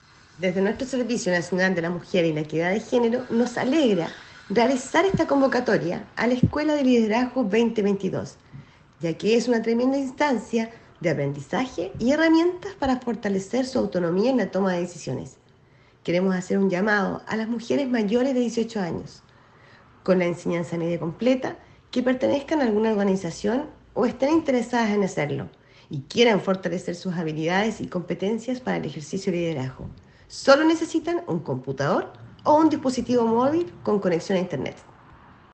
Las capacitaciones se realizarán entre noviembre y diciembre de este año, en modalidad online. Al respecto la Directora Regional de SernamEG, señaló “desde nuestro Servicio Nacional de la Mujer y la Equidad de Género nos alegra realizar esta convocatoria a la Escuela de Liderazgo 2022, ya que es una tremenda instancia de aprendizajes y herramientas para fortalecer su autonomía en la toma de decisiones. Hacemos un llamado a mujeres mayores de 18 años, con la enseñanza media completa, que pertenezcan a alguna organización – o estén interesadas en hacerlo – y quieran fortalecer sus habilidades y competencias para el ejercicio del liderazgo. Sólo necesitan un computador o un dispositivo móvil con conexión a internet” explicó la autoridad.